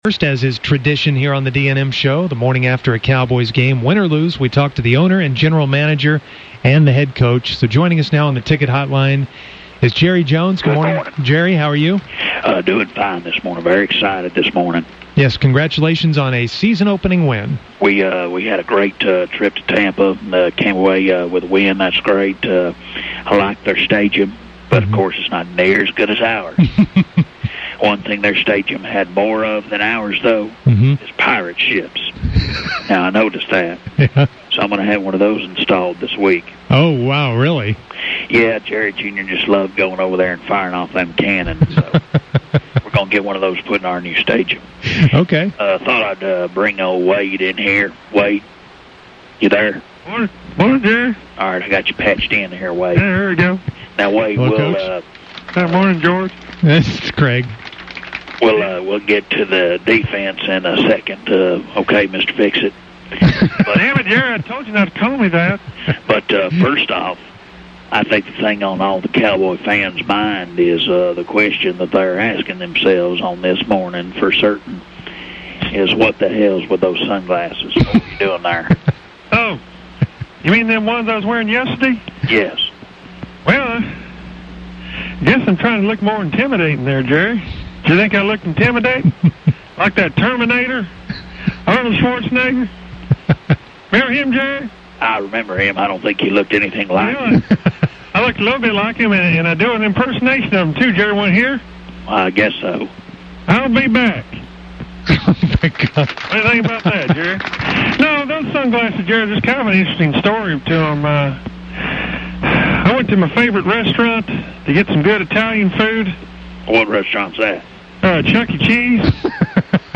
Then Wade comes on and they ask him about his horrible sun glasses. He says it’s him doing the terminator, then does an imitation. Wade explains where he got the sun glasses which came from Chuckie Cheese.